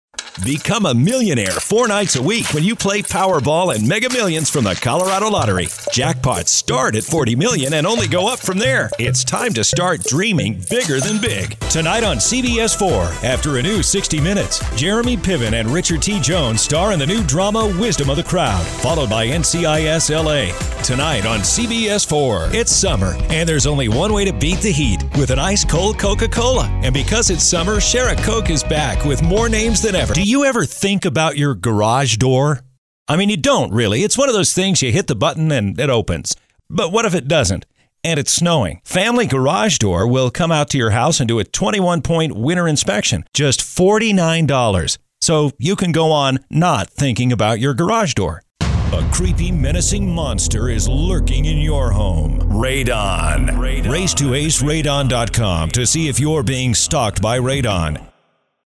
Full time American Voiceover actor and fictional character
Commercials
English - USA and Canada